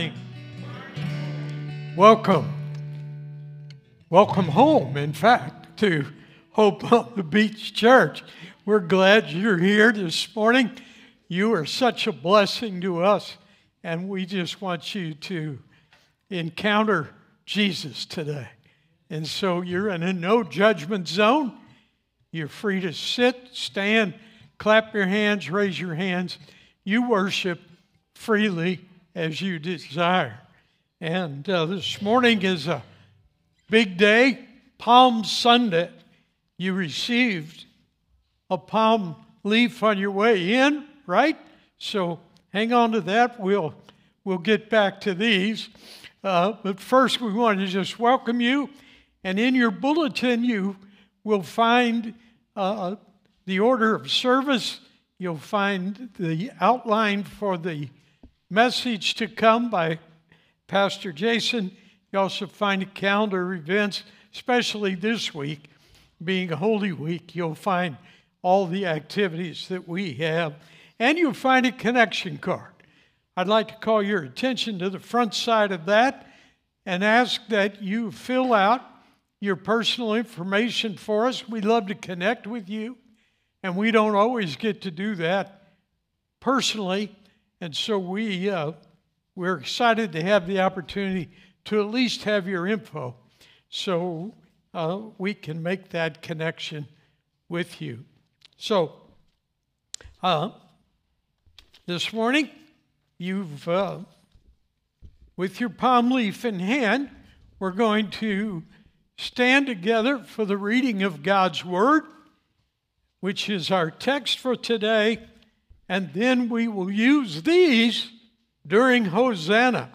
PALM SUNDAY 2026 Current Sermon WHO IS THE KING?